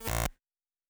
pgs/Assets/Audio/Sci-Fi Sounds/Electric/Glitch 1_02.wav at 7452e70b8c5ad2f7daae623e1a952eb18c9caab4
Glitch 1_02.wav